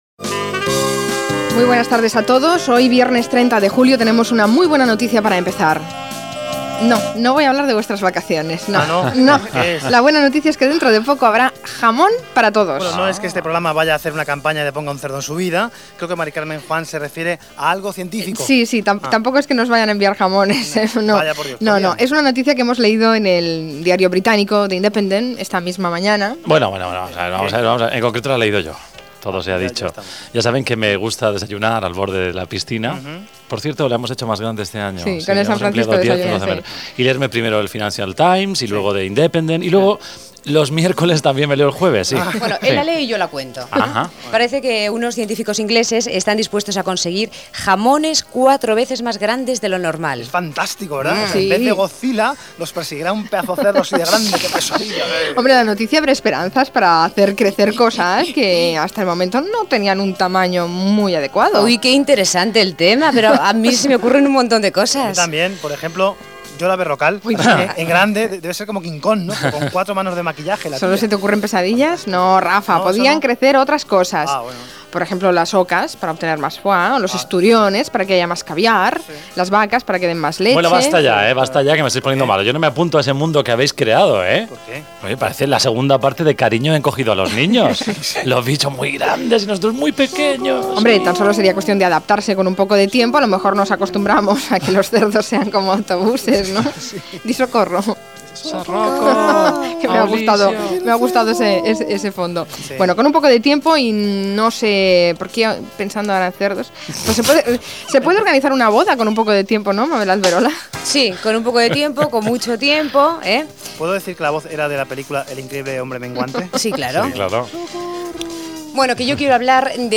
Entreteniment
Programa presentat per Julia Otero.